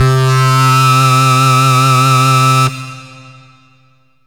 SYNTH LEADS-1 0005.wav